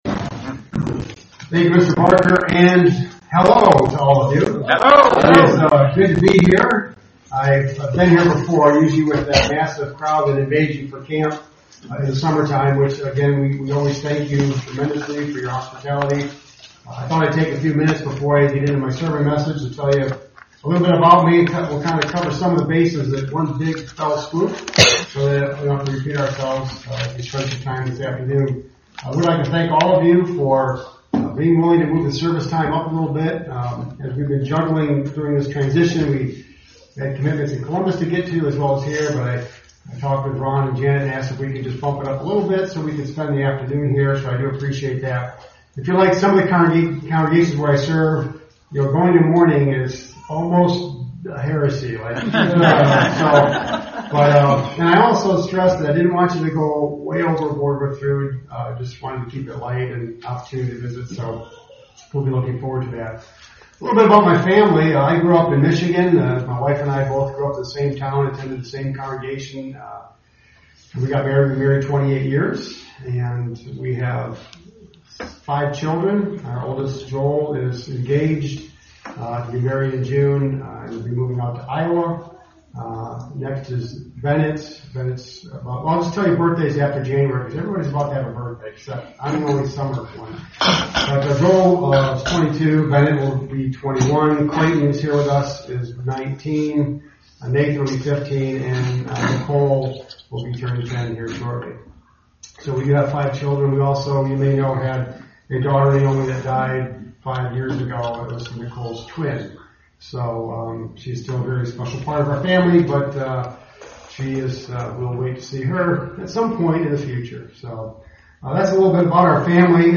Are there different types of Fear towards God? This sermon explains what it means to fear God.